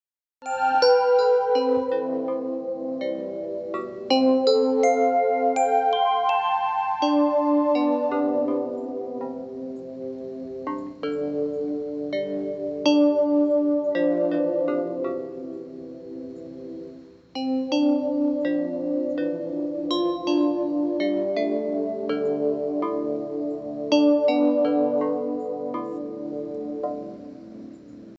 La Música de las Plantas es una experiencia sensorial liderada por Fundación Impulso Verde Kuaspue que permite escuchar las frecuencias eléctricas de las plantas convertidas en música, generando una conexión profunda con la vida vegetal.
Con tecnología especializada, estas señales se transforman en sonido donde cada interpretación es única, influenciada por el ambiente y las personas presentes.